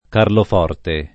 [ karlof 0 rte ]